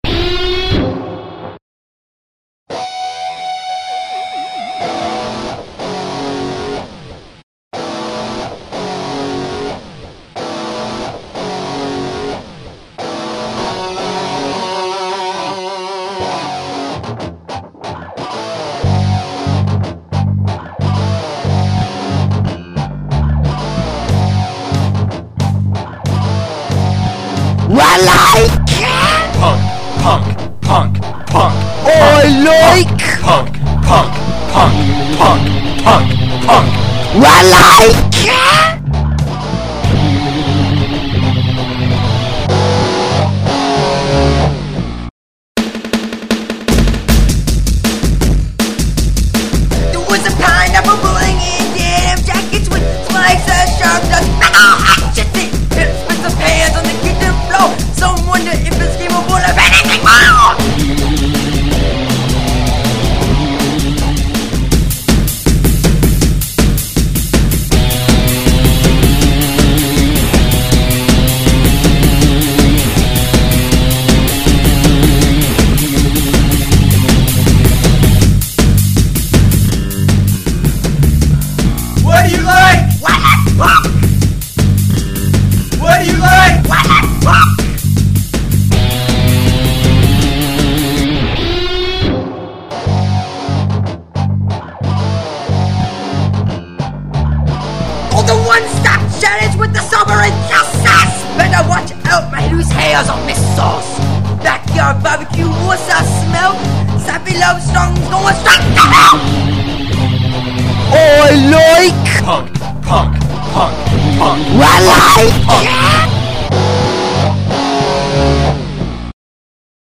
Drums
Guitar
Vocals
Keyboard